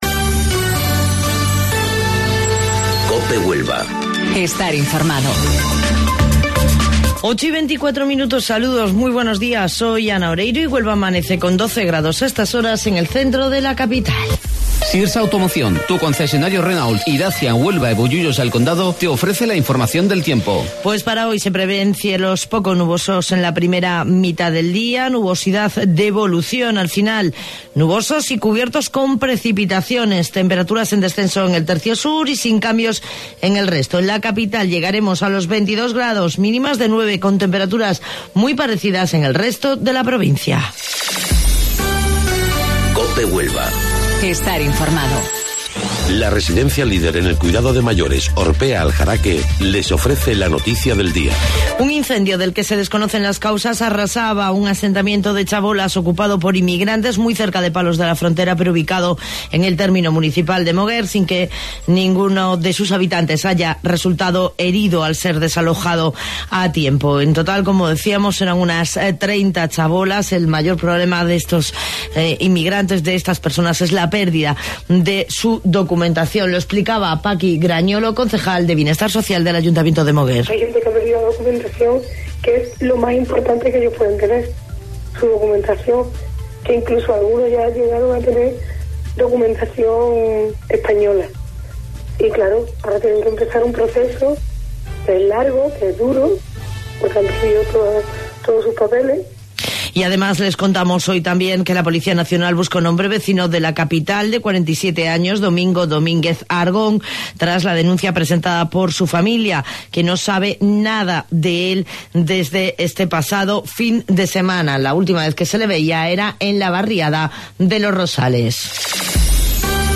AUDIO: Informativo Local 08:25 del 22 de Abril